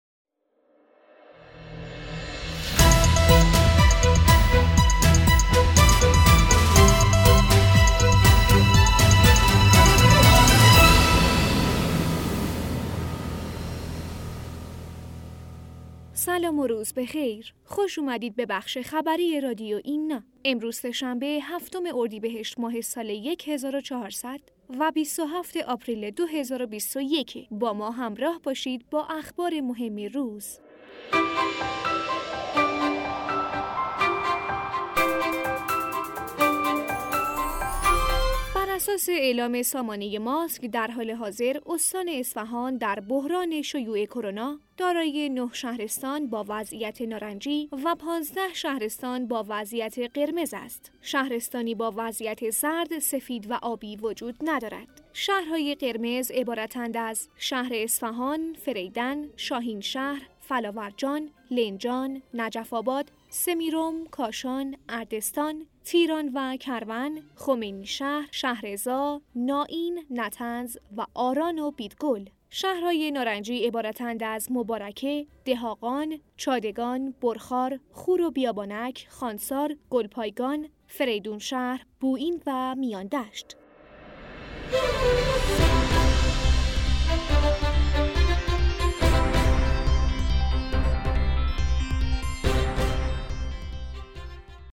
گوینده